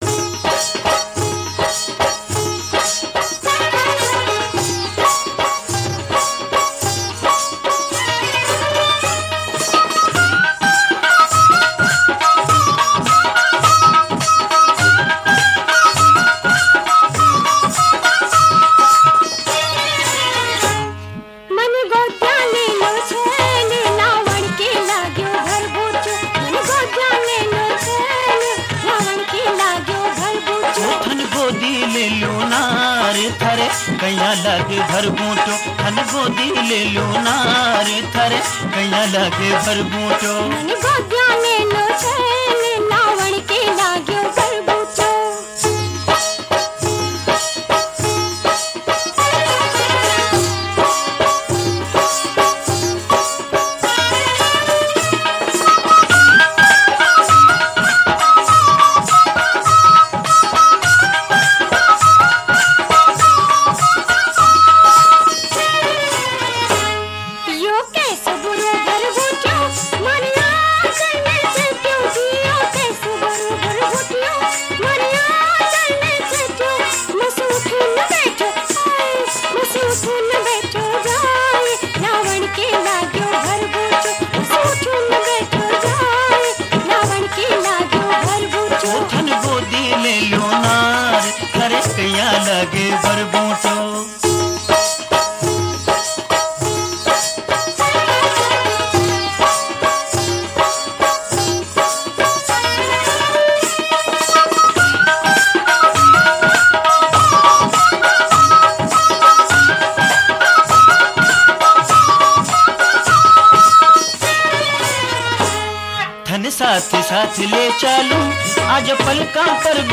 Rajasthani Songs
(Live)